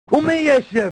Worms speechbanks
revenge.wav